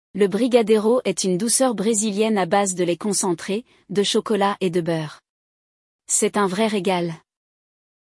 Durante o episódio, você ouvirá um diálogo autêntico sobre a culinária brasileira, com expressões úteis para quando precisar falar sobre comida em francês.